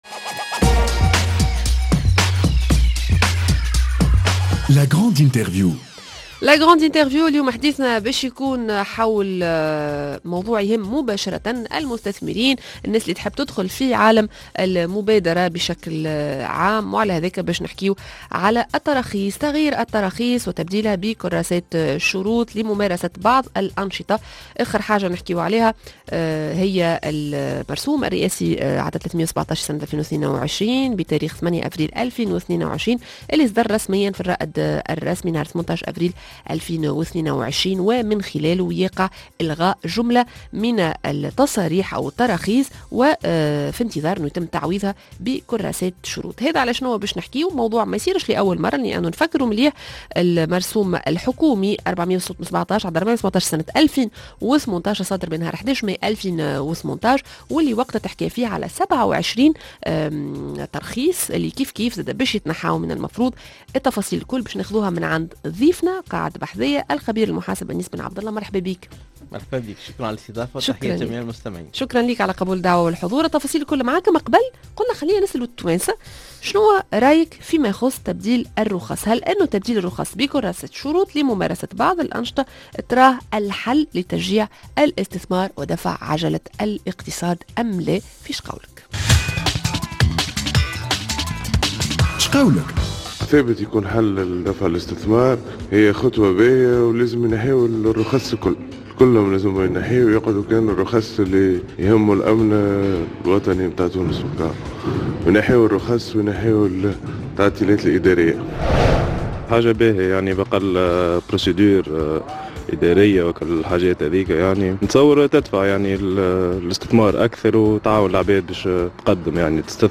La grande interview: بدلنا التراخيص بكراسات شروط وبعد؟